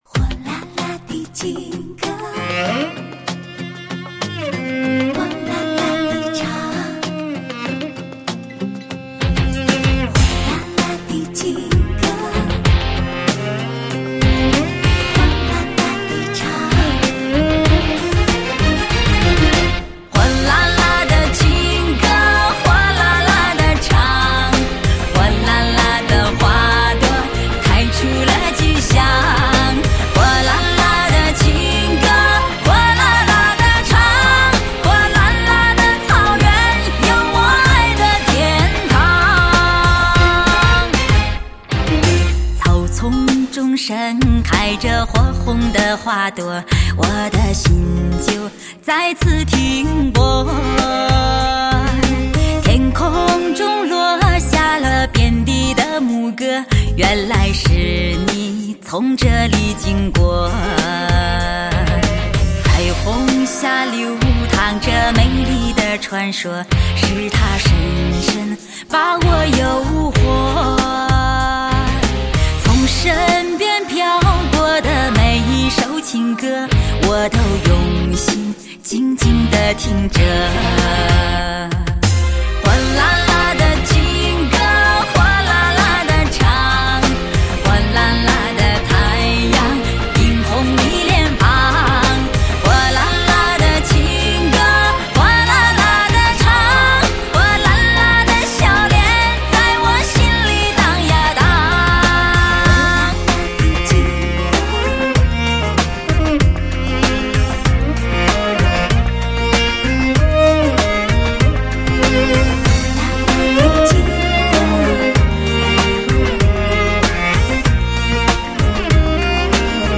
纯净而动感的草原节奏风
纯净而动感的草原节奏风，来自人间天堂的美丽嗓音，火辣而浓烈的草原气息！
来自天堂的美丽嗓音，亲切而自然的演绎